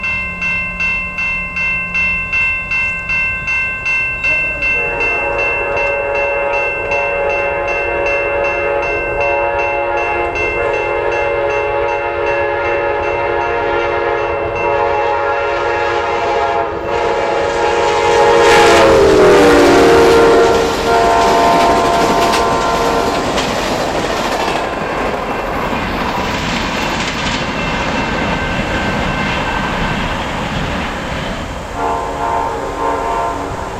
passenger train bells
field railroad recording tracks train transportation sound effect free sound royalty free Nature